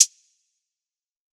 Closed Hats
YM Hi-hat 3.wav